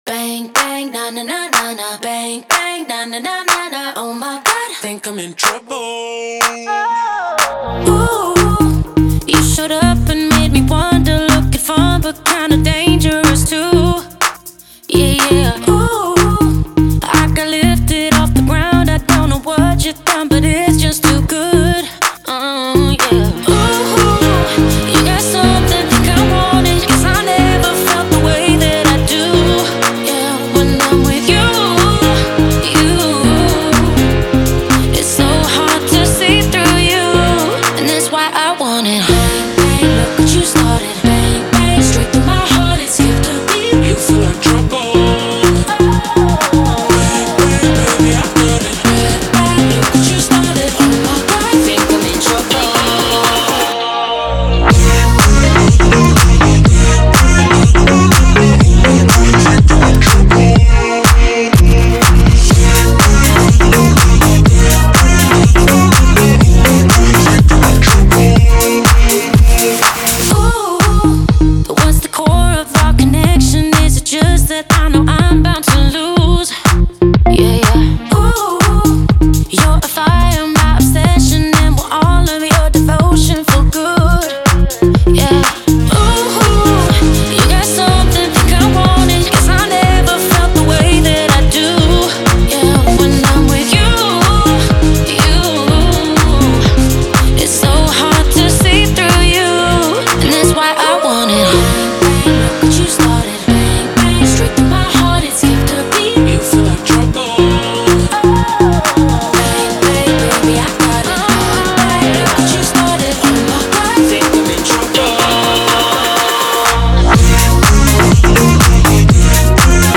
это энергичный трек в жанре EDM и поп